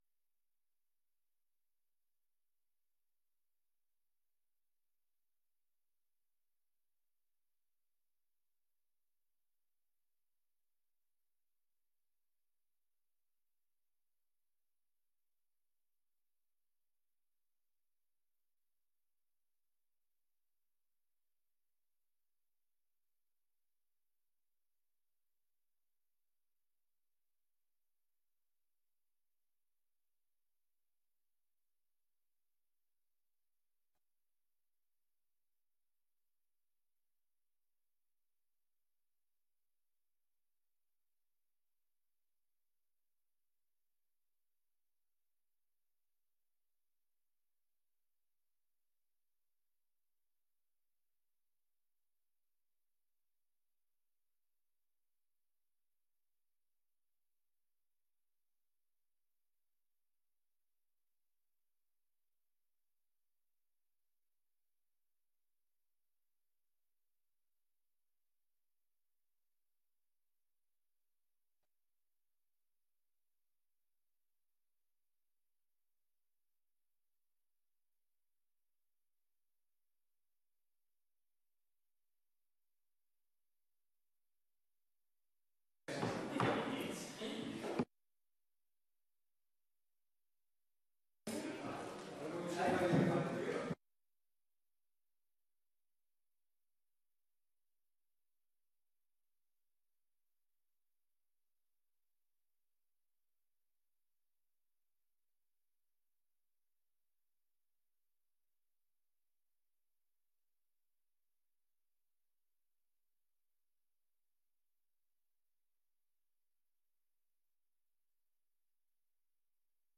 Oude raadzaal 16:25 - 16:48